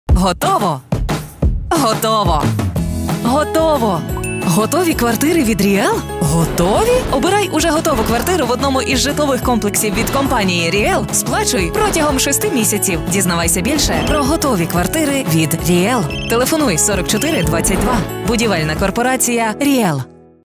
Ukrainisch, ukrainian, native speaker, Mutterspachler, vertauerlich, empathic, empathysch
Sprechprobe: Werbung (Muttersprache):